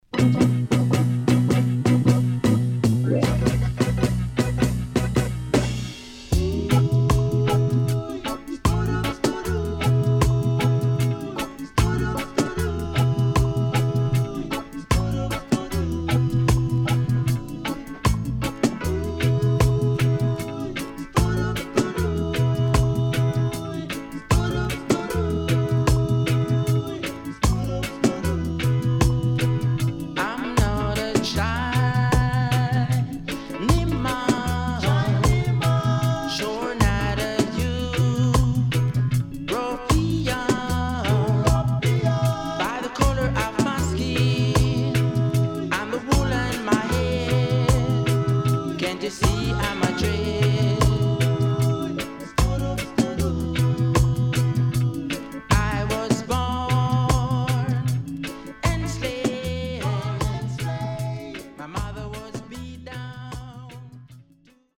ソウルフルで爽やかなStepper Reggae名盤です。